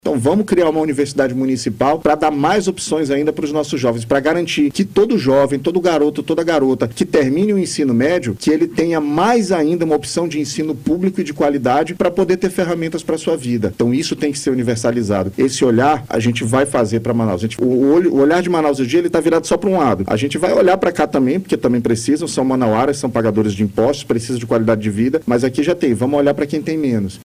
Entrevista
A entrevista exclusiva ocorreu nesta quinta-feira, 09, durante o BandNews Amazônia 1ª Edição.